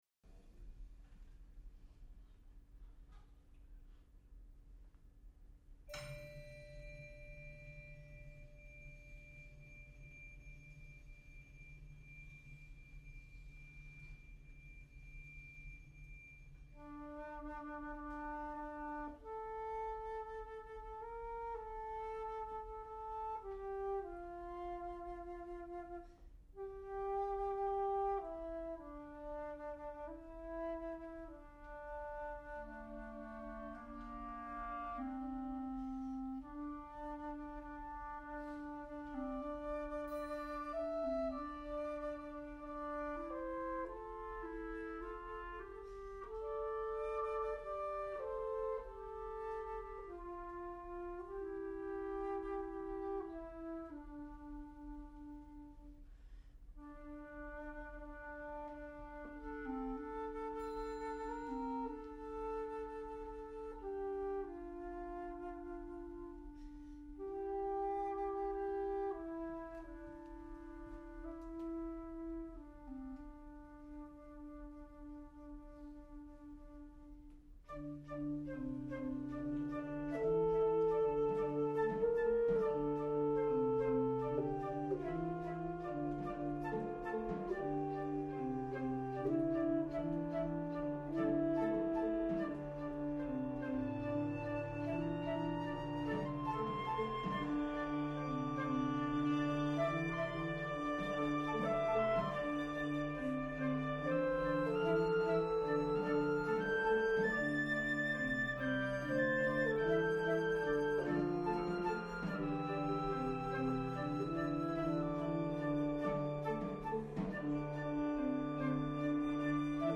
Genre: Band
Percussion 2 (large triangle, tam-tam, whip)